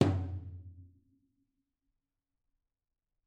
TomL_HitS_v4_rr2_Mid.mp3